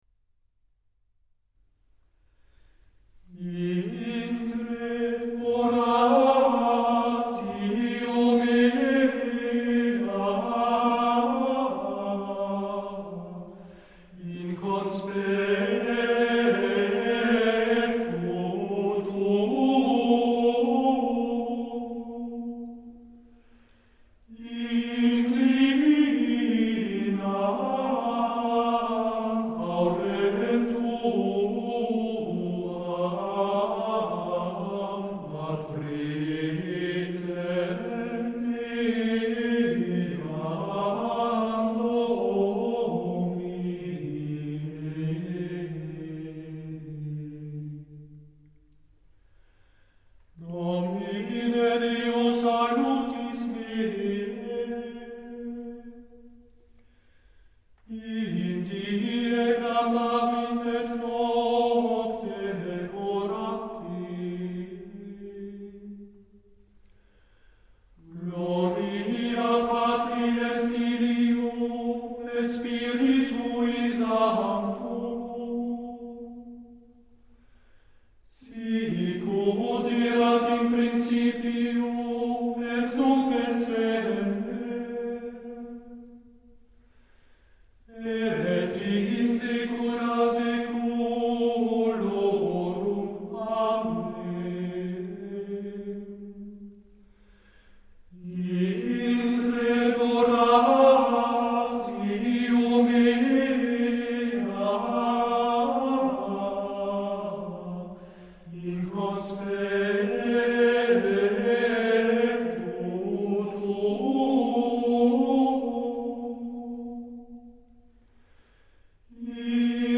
Plainchant
Group: A capella
Introitus
Playlist includes music from genres: Gregorian chant